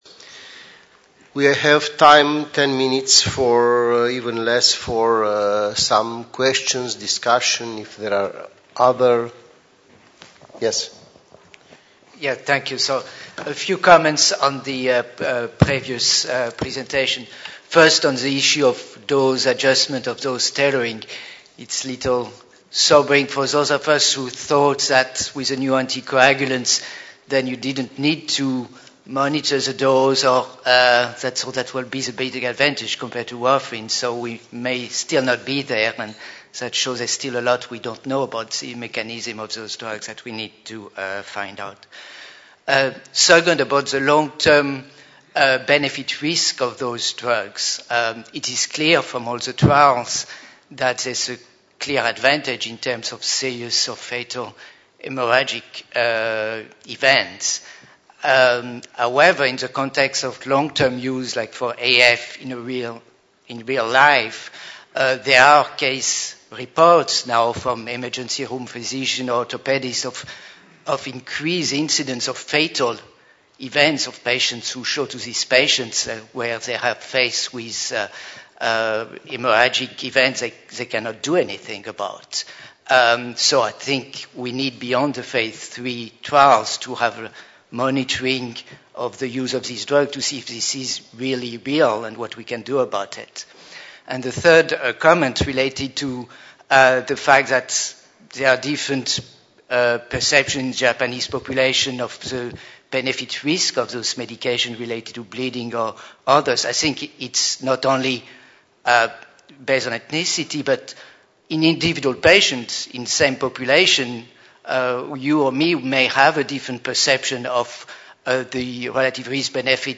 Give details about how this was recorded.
Cardiovascular Clinical Trialists (CVCT) Forum – Paris 2012 - Workshop 1 : Dosing Issues, Questions | Canal U